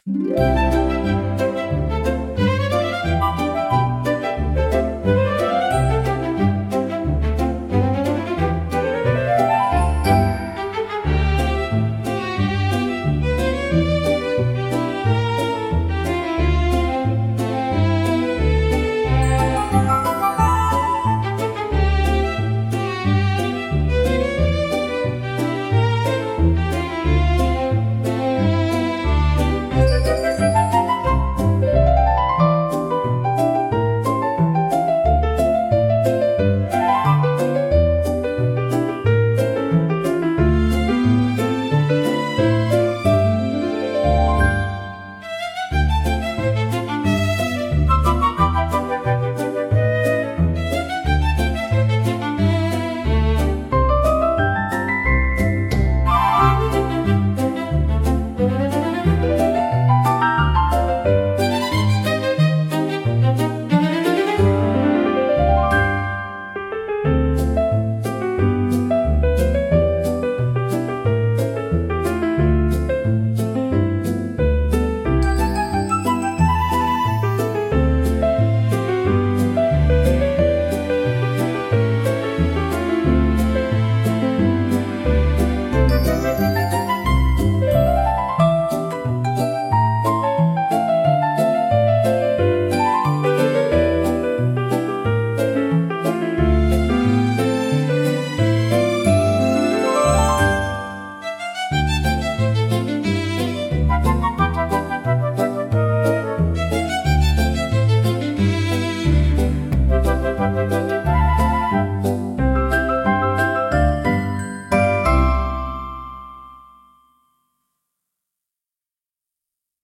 オリジナルの朝ジャンルは、しっとりと穏やかでありながら希望に満ちた明るい曲調が特徴です。
優しいメロディと柔らかなアレンジが一日の始まりを穏やかに包み込み、聴く人に前向きな気持ちをもたらします。
静かで清々しい空気感を演出しつつ、心に明るい希望や期待を芽生えさせる効果があります。